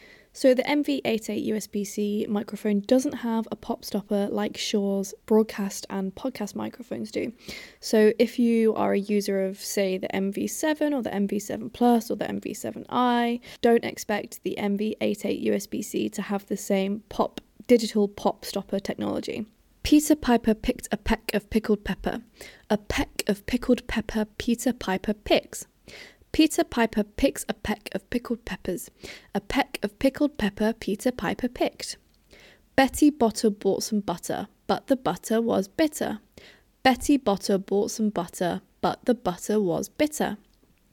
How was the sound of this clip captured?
To test the MV88 USB-C, I recorded myself saying a series of ‘B’ and ‘P’ heavy tongue twisters. As you can hear, the MV88 USB-C minimizes harsh popping sounds. The clip is easy to listen to, and doesn’t have me wincing or turning the volume down due to undesired pops.